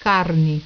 Click the button below to hear the pronunciation of the word